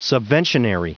Prononciation du mot subventionary en anglais (fichier audio)
Prononciation du mot : subventionary